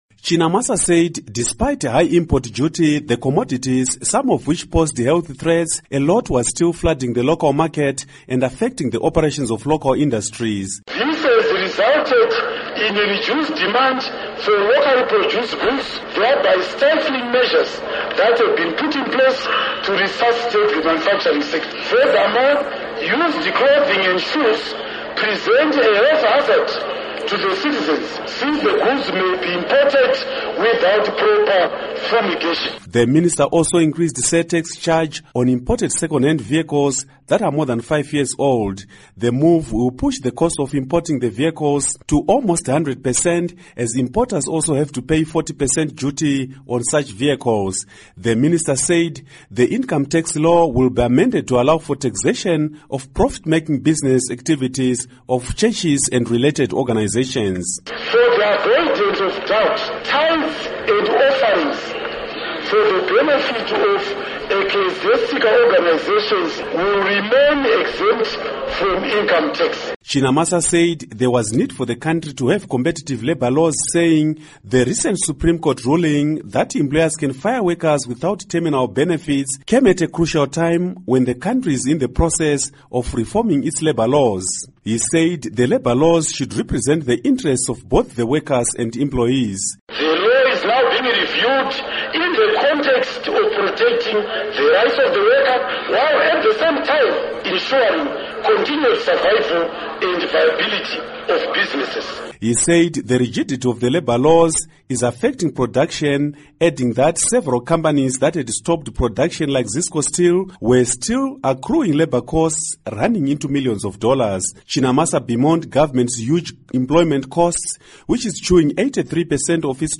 Report on Fiscal Policy Review Field